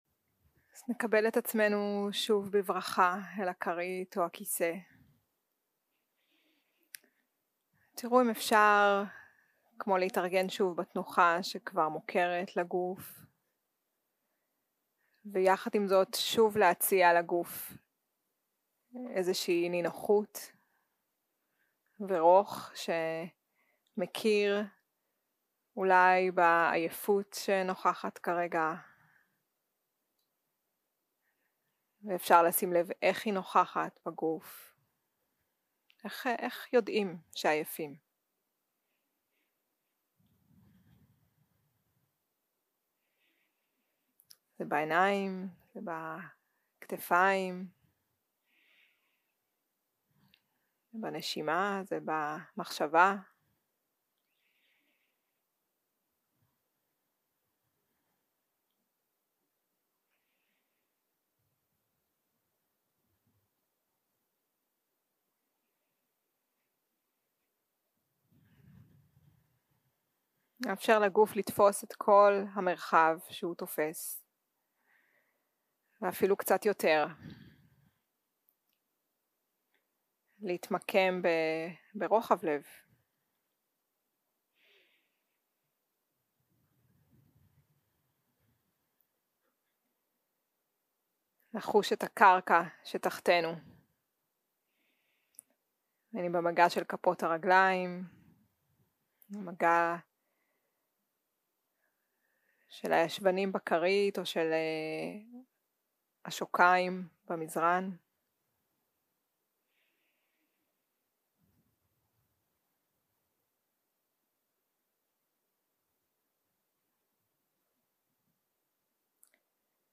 מדיטציה מונחית